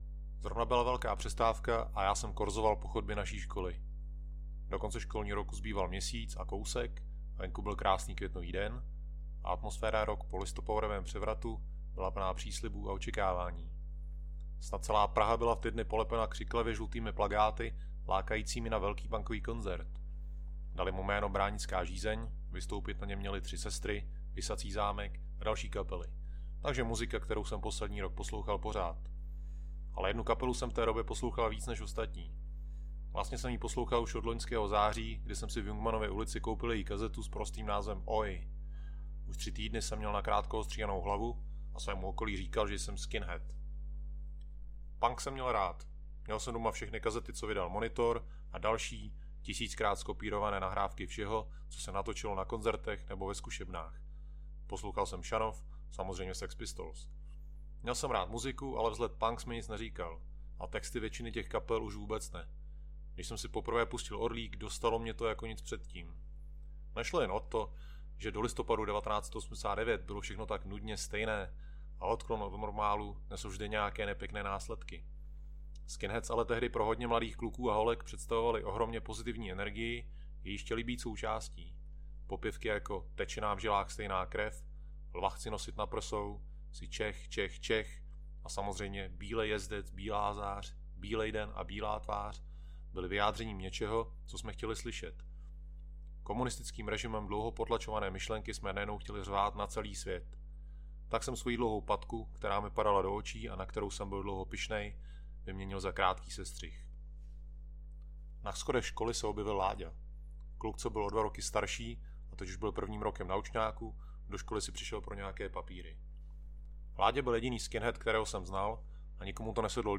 Ukázka z knihy
Kvalita záznamu nedosahuje standardu profesionálních studií.